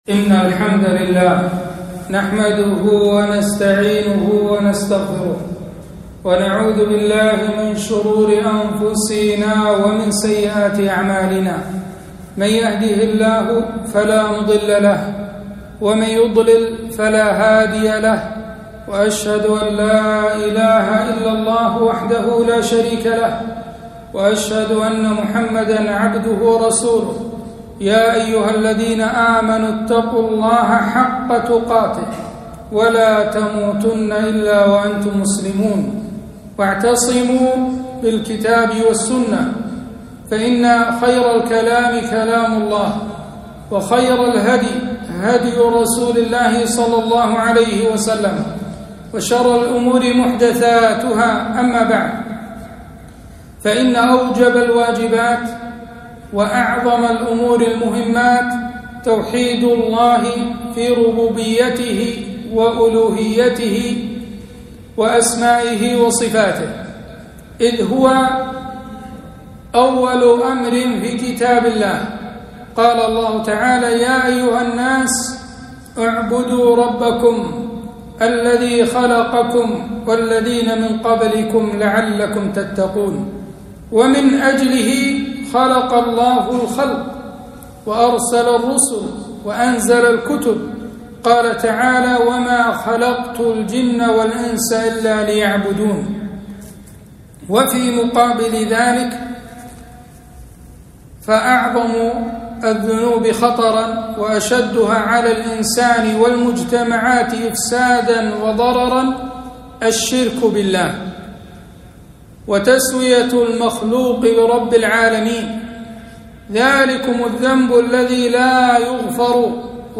خطبة - خطورة الشرك الأصغر وبعض أنواعه